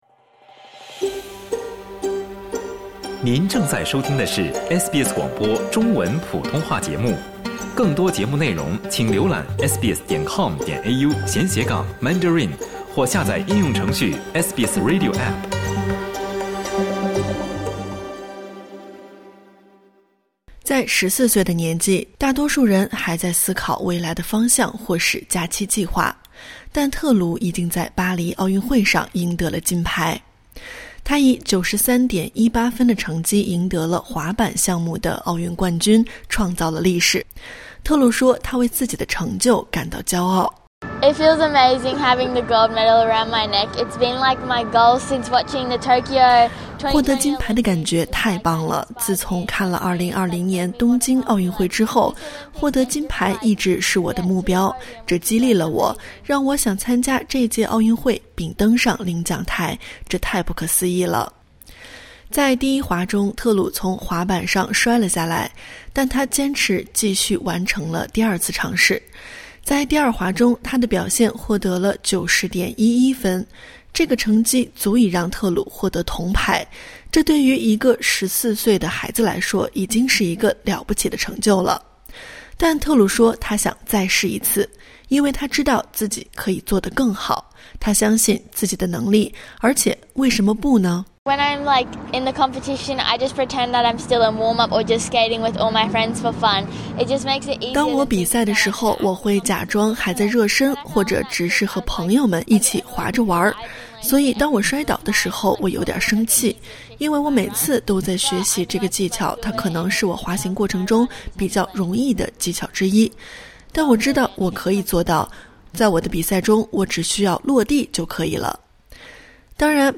点击音频，收听完整报道。